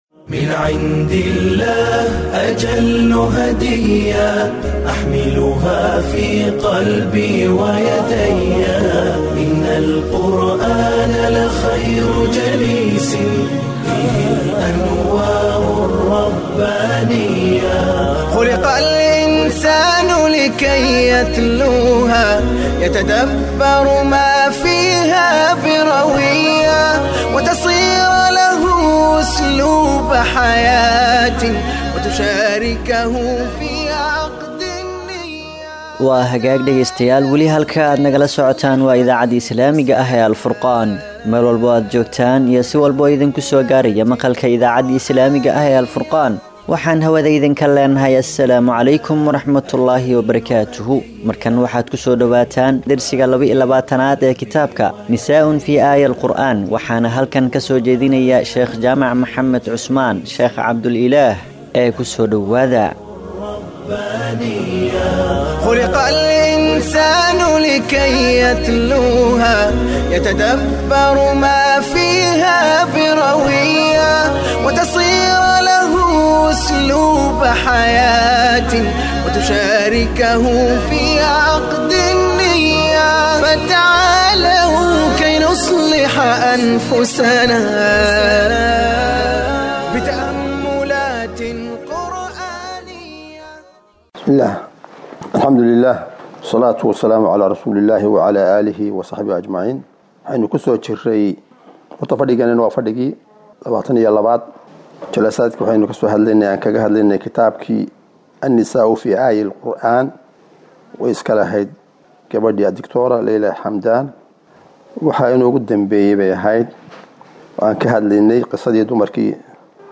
Inta lagu guda jiro bishaan waxaan dhageystayaasheenna ugu tala galnay duruus Ramadaani ah